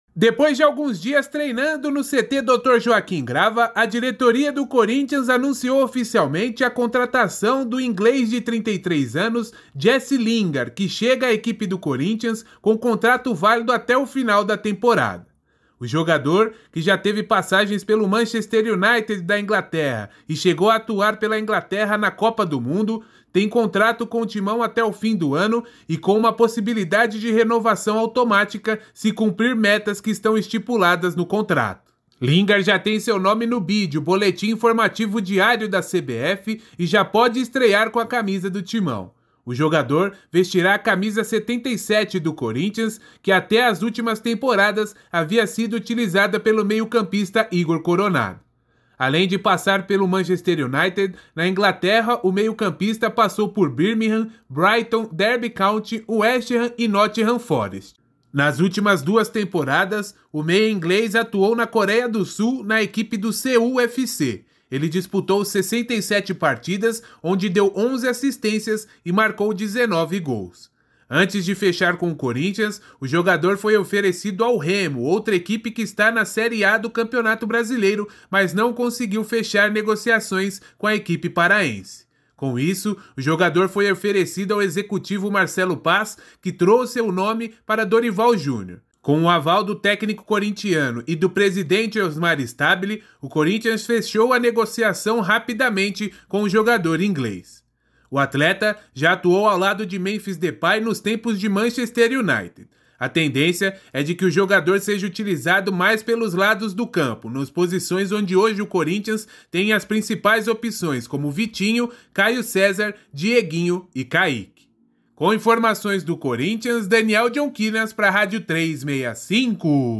Boletin em áudio